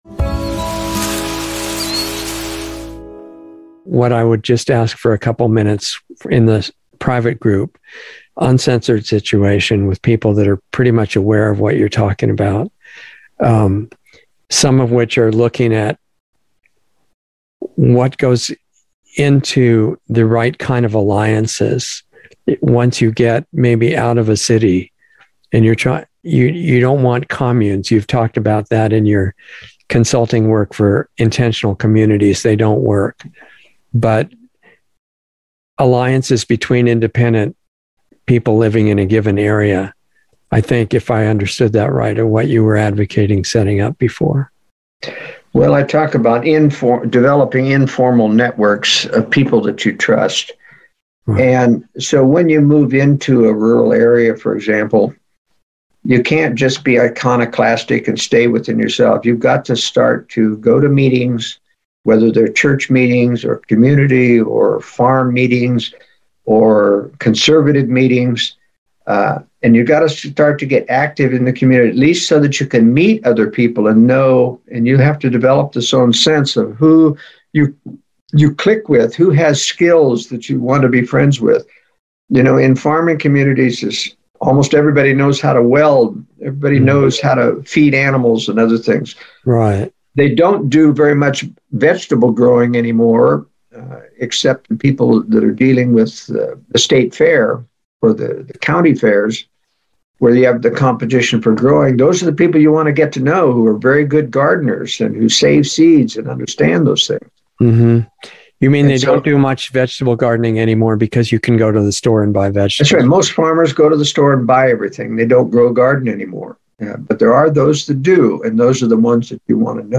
Insider Interview 1/21/22